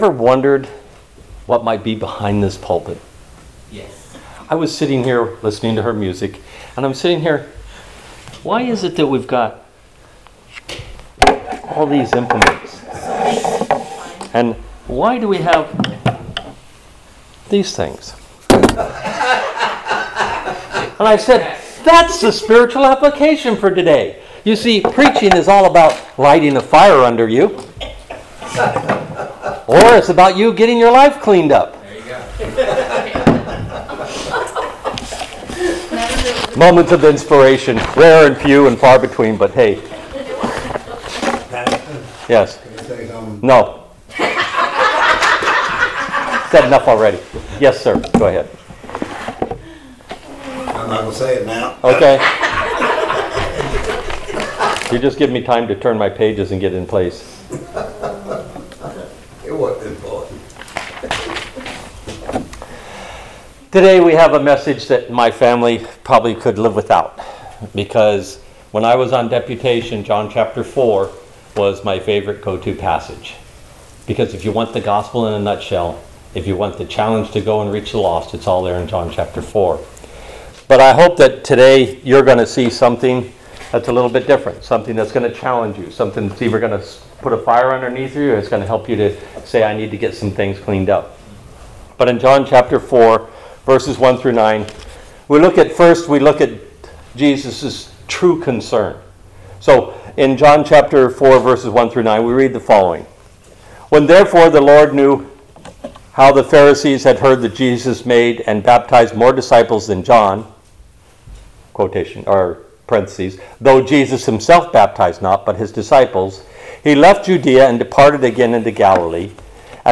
Living Water at the Well John (4:1-23) — Montague Baptist Church